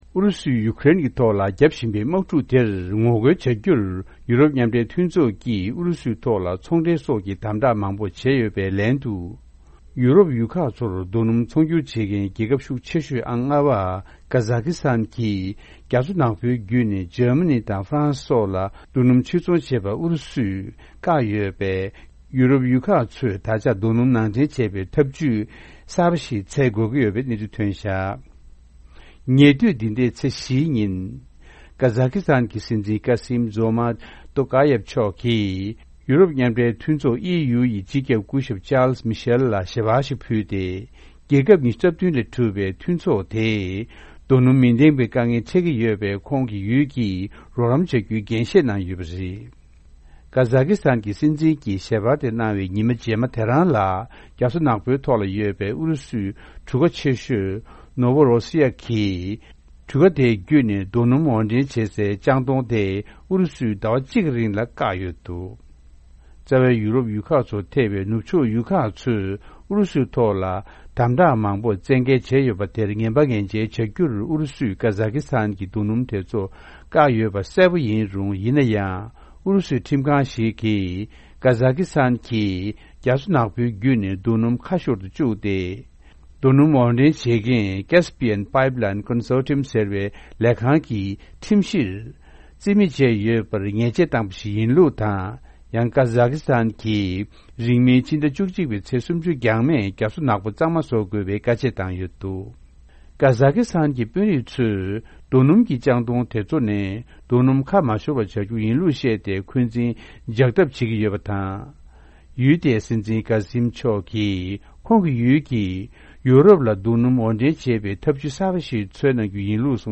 འཛམ་གླིང་གསར་འགྱུར།